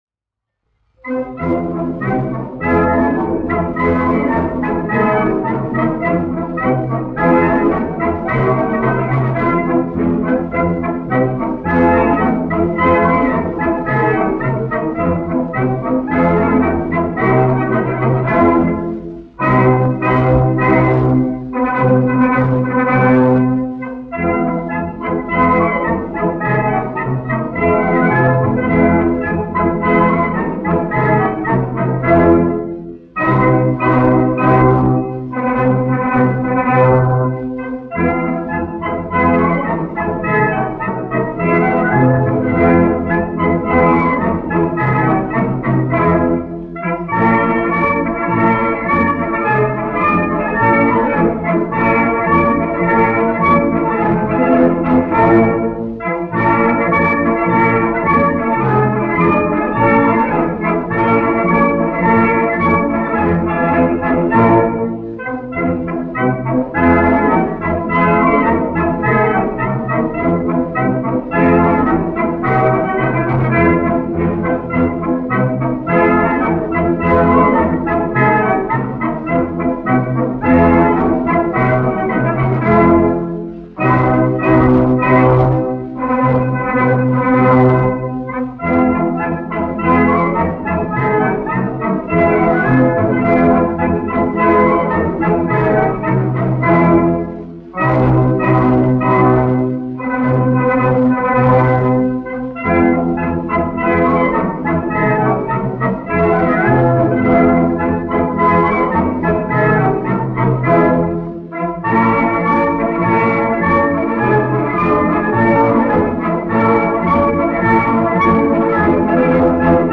1 skpl. : analogs, 78 apgr/min, mono ; 25 cm
Pūtēju orķestra mūzika
Marši
Skaņuplate